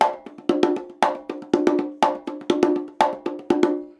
Index of /90_sSampleCDs/USB Soundscan vol.36 - Percussion Loops [AKAI] 1CD/Partition B/14-120CONGAS
120 CONGAS17.wav